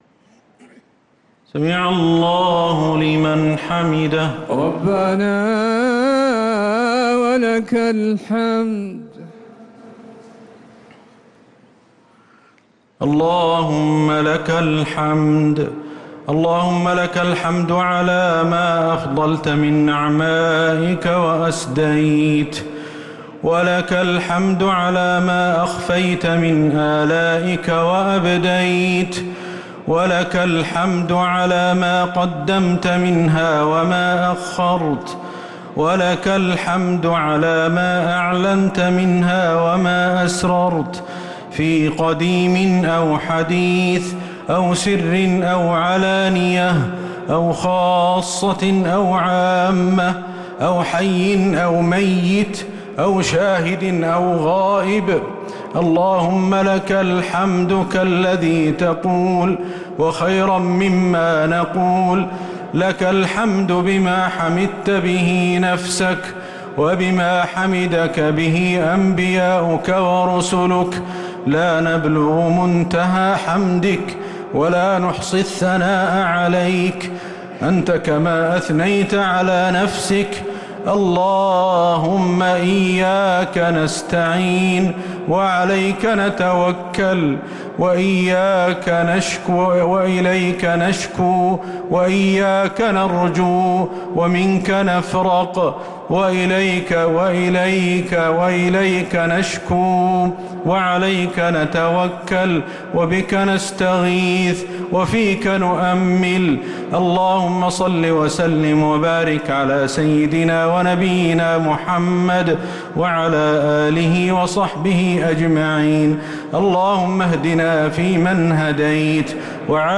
دعاء القنوت | تراويح رمضان